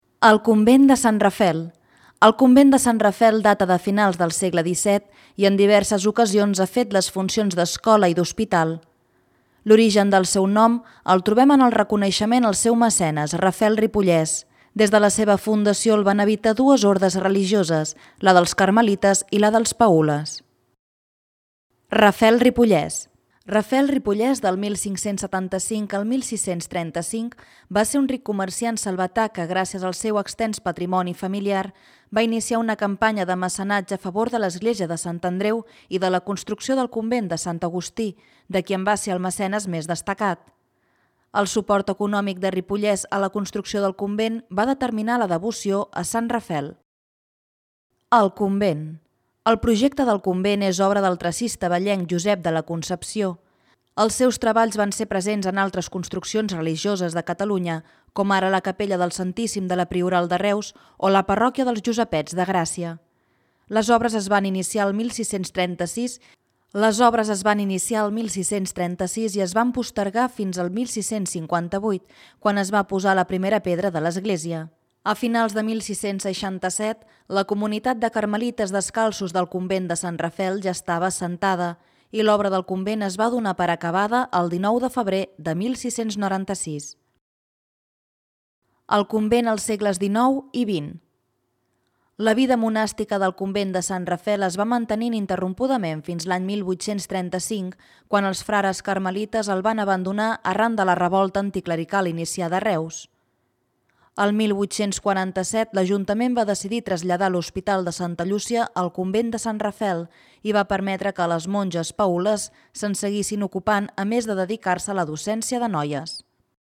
Audio guia